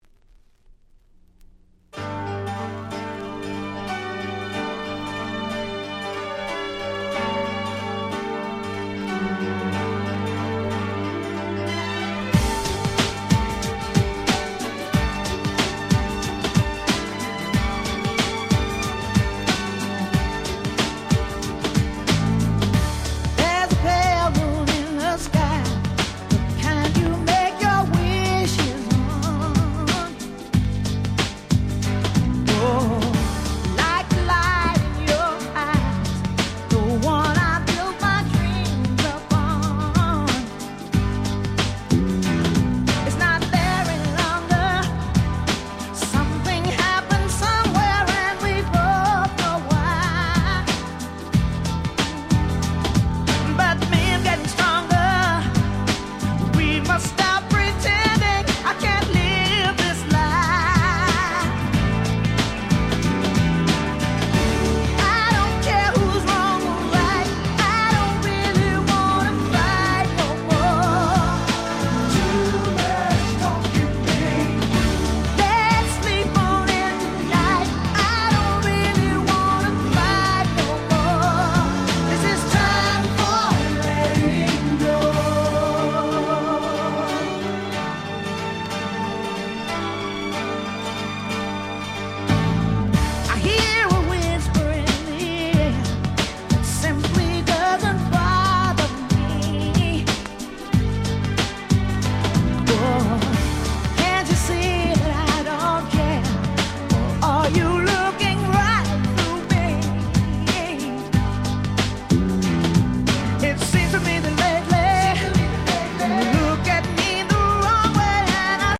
テーマは「グラウンドビート風味のPopsヒット」と言った所でしょうか。